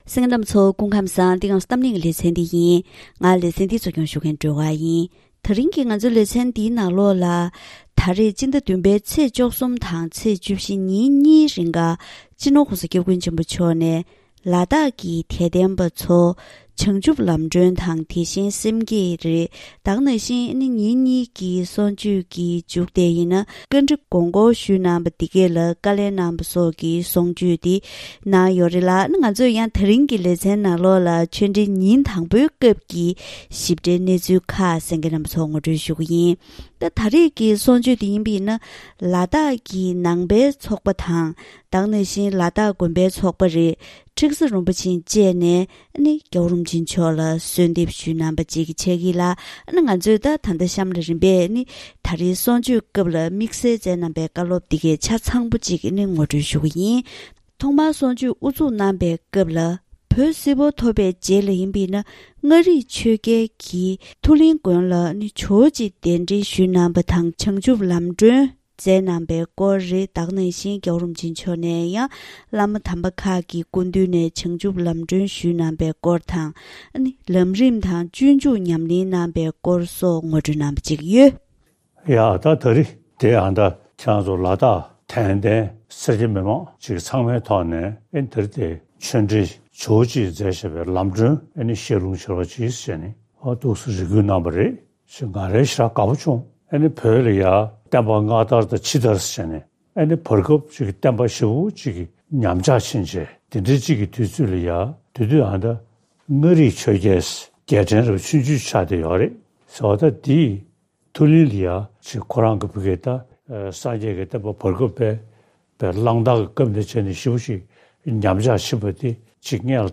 ད་རིང་གི་གཏམ་གླེང་ཞལ་པར་ལེ་ཚན་ནང་སྤྱི་ནོར་༧གོང་ས་༧སྐྱབས་མགོན་ཆེན་པོ་མཆོག་གིས་བཞུགས་སྒར་ཕོ་བྲང་ནས་དྲ་ལམ་ཐོག་ལ་དྭགས་ཀྱི་དད་ལྡན་ཆོས་ཞུ་བར་བྱང་ཆུབ་ལམ་སྒྲོན་གྱི་བཀའ་ཆོས་དང་། ཆོས་ཞུ་བས་བཀའ་འདྲི་ཞུ་རྒྱུའི་གོ་སྐབས་ཀྱང་གནང་ཡོད།